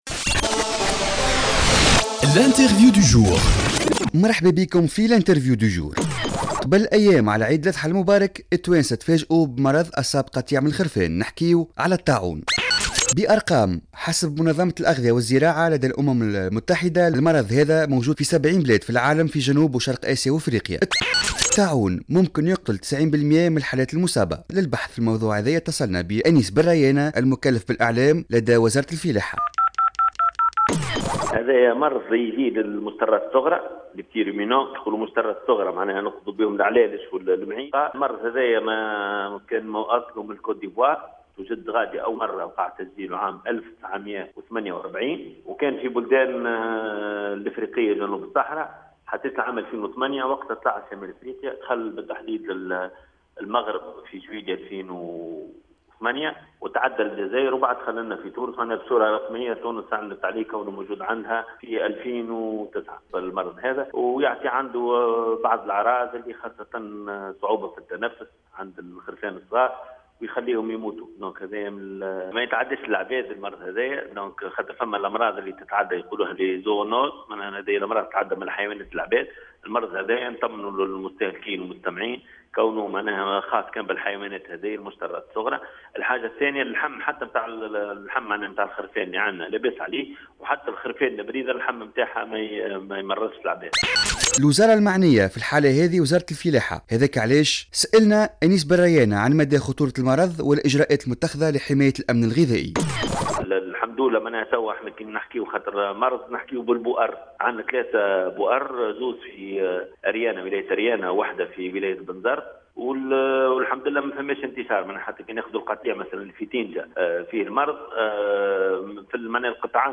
مداخلة له على الجوهرة "اف ام"